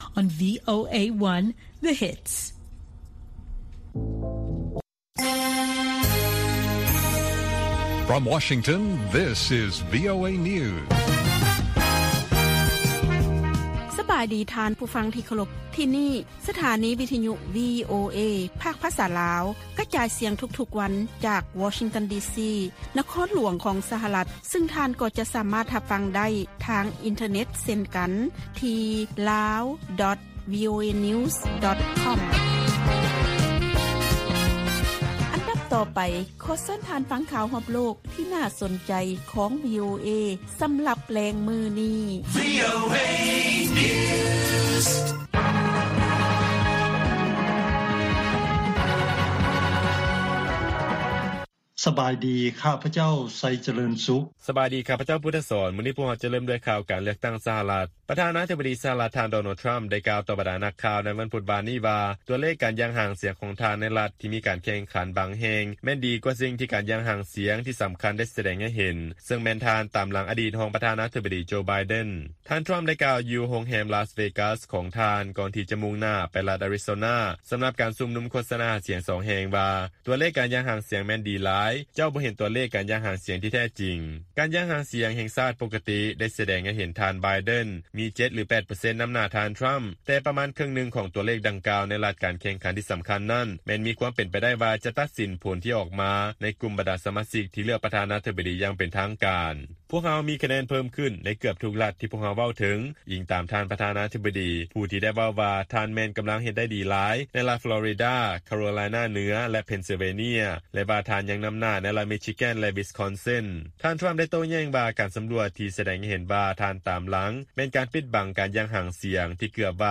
ລາຍການກະຈາຍສຽງຂອງວີໂອເອ ລາວ